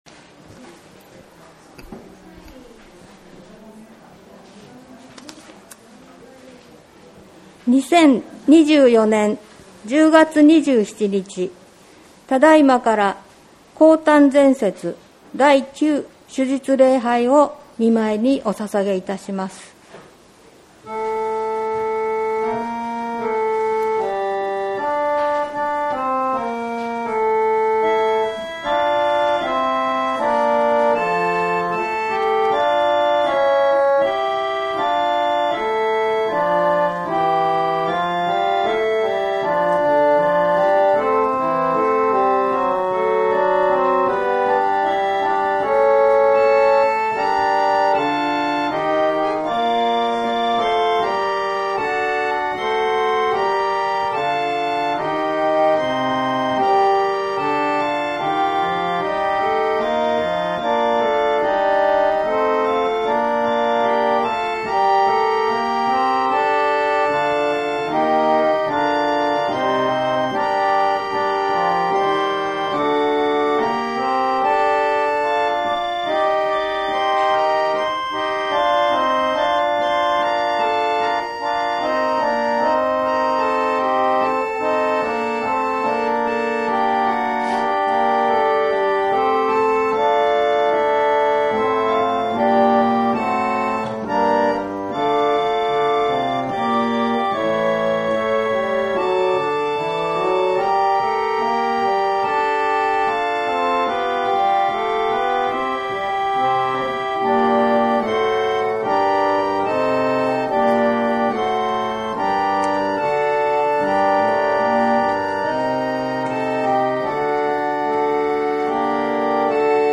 2024年7月14日礼拝音源配信はこちら